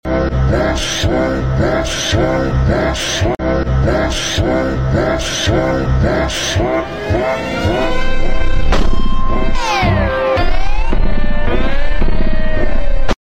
flute_beatbox
flute recorder